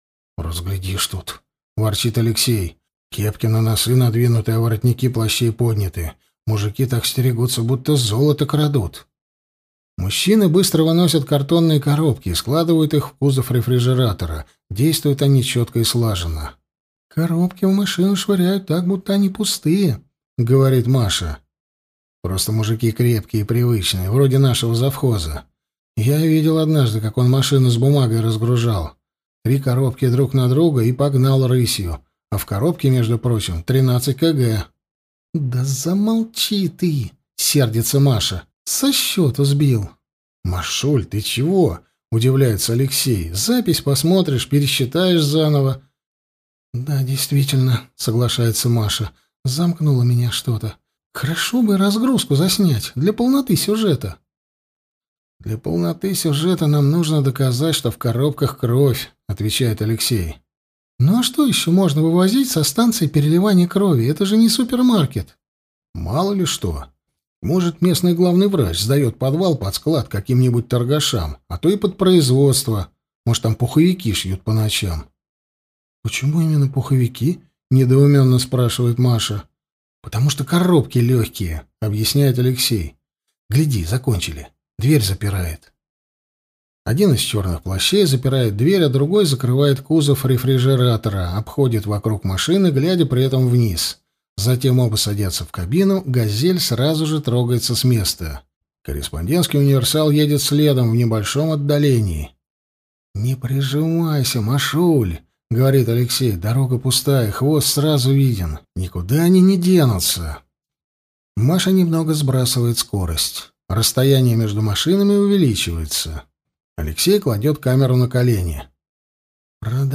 Аудиокнига Отдай мою кровь | Библиотека аудиокниг